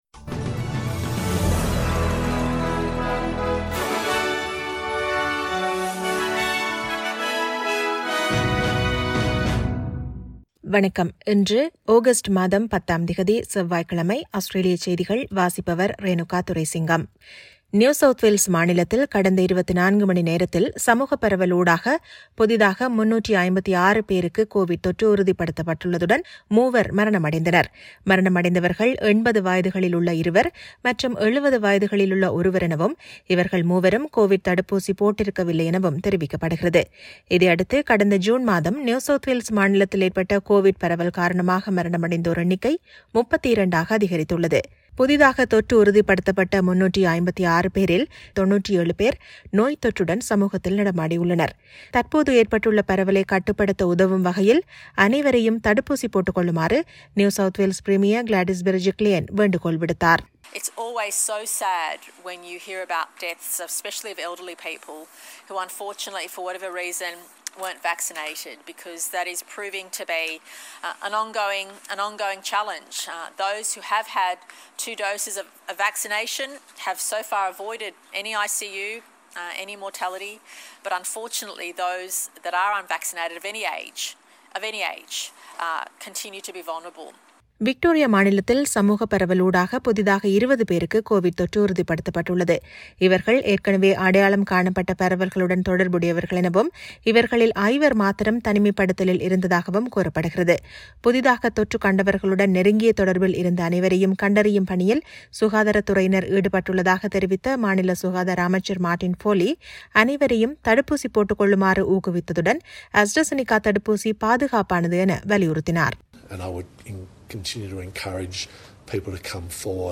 SBS தமிழ் ஒலிபரப்பின் இன்றைய (செவ்வாய்க்கிழமை 10/08/2021) ஆஸ்திரேலியா குறித்த செய்திகள்.